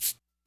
Havoc Shaker.wav